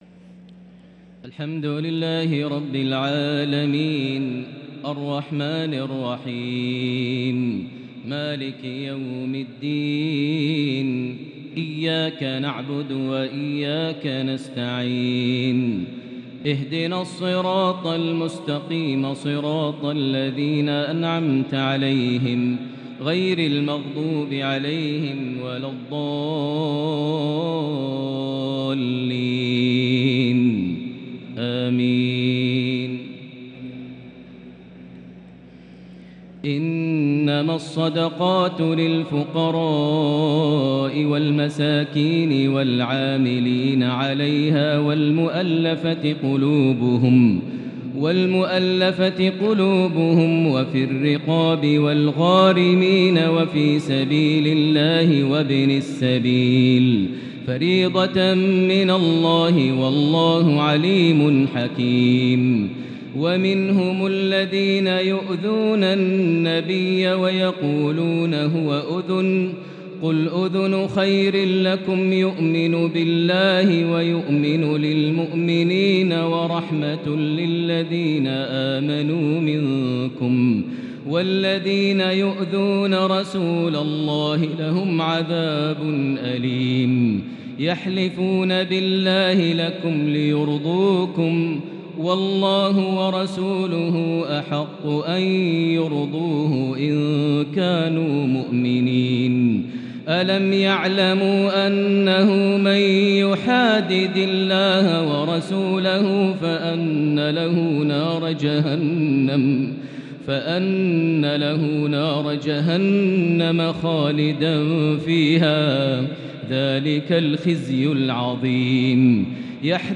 تراويح ليلة 14 رمضان 1443هـ| سورة التوبة (60-116) |Taraweeh 14st night Ramadan 1443H -Surah At-Taubah 60-116 > تراويح الحرم المكي عام 1443 🕋 > التراويح - تلاوات الحرمين